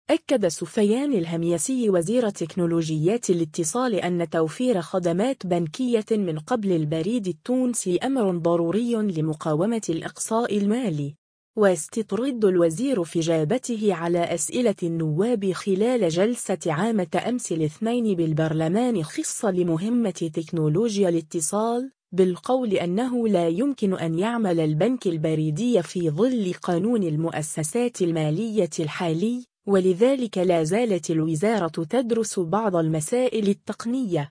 واستطرد الوزير في اجابته على أسئلة النواب خلال جلسة عامة أمس الاثنين بالبرلمان خصصة لمهمة تكنولوجيا الاتصال، بالقول أنه لا يمكن أن يعمل البنك البريدي في ظل قانون المؤسسات المالية الحالي، ولذلك لازالت الوزارة تدرس بعض المسائل التقنية.